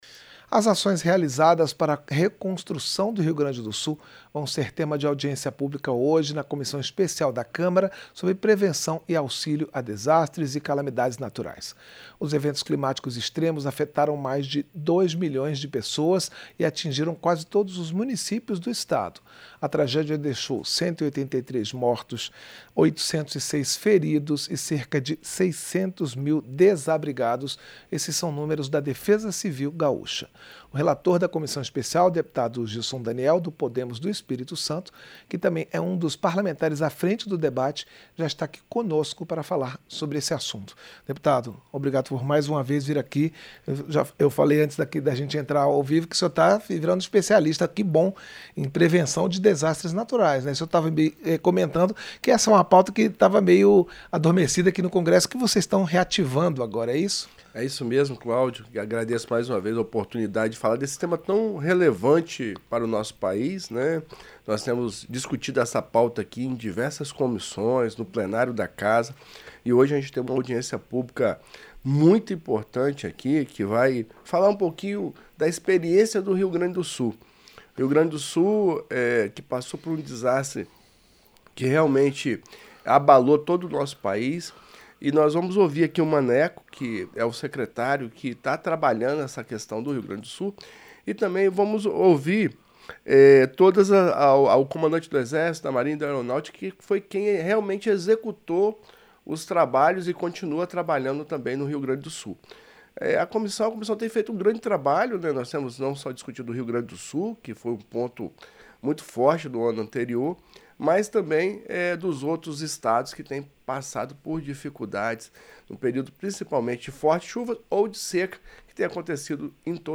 Entrevista - Dep. Gilson Daniel (Podemos-ES)